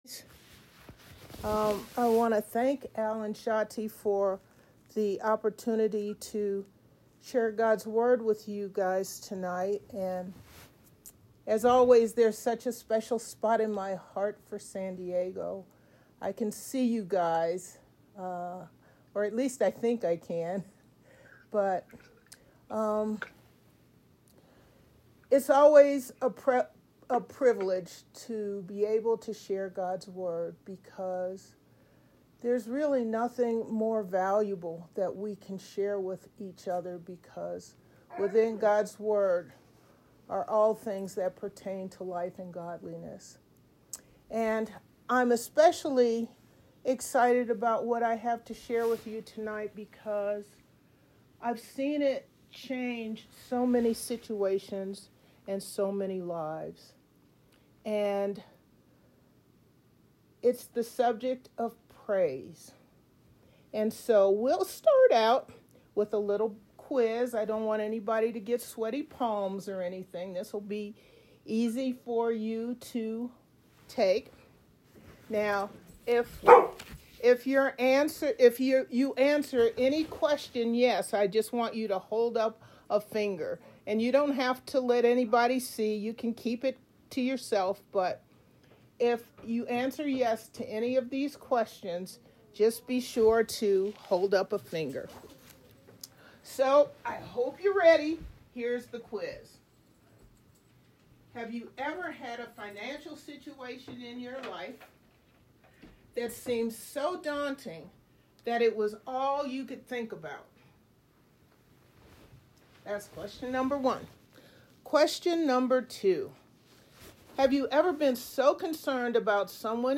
Details Series: Conference Call Fellowship Date: Monday, 02 December 2024 Hits: 289 Scripture: Isaiah 26:3 Play the sermon Download Audio ( 91.07 MB )